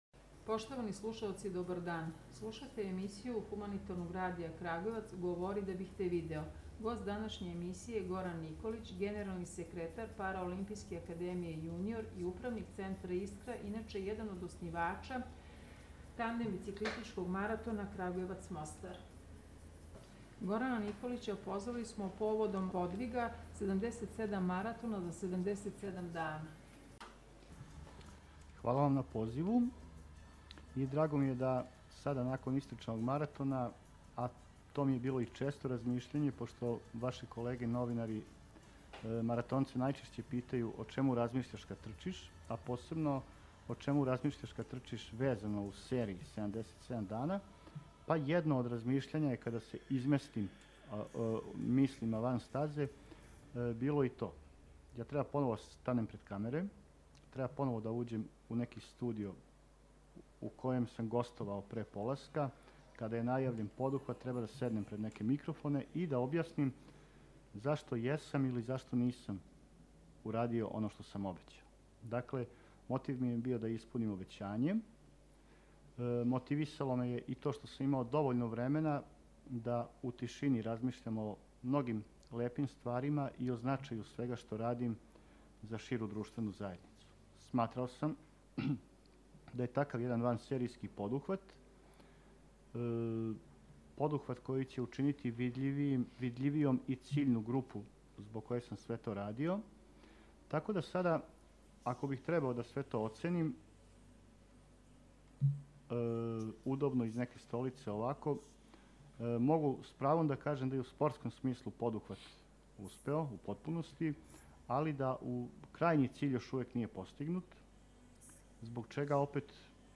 gostovao je u emisiji Humanitarnog radija “Govori da bih te video.”